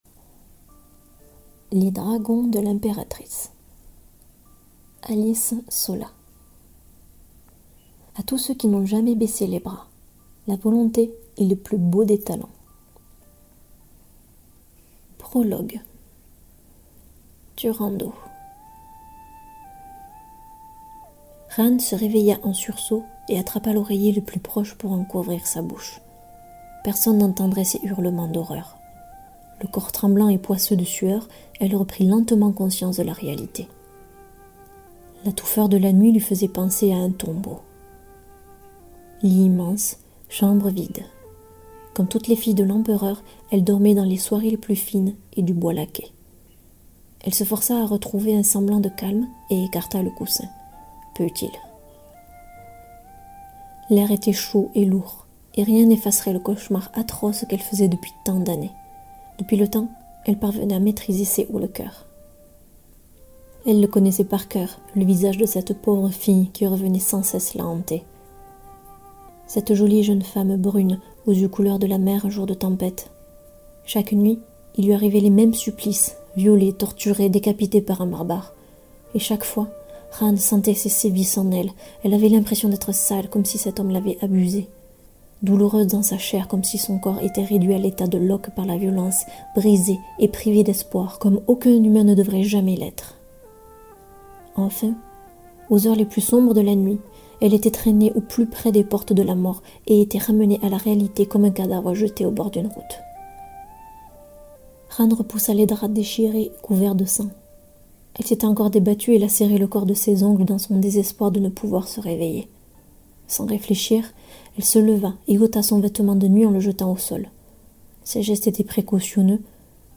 Découvrez le prologue en extrait audio.